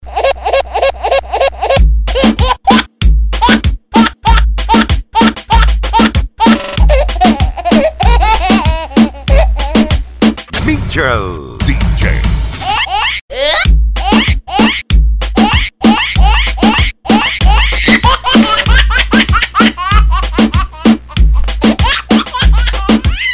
جلوه های صوتی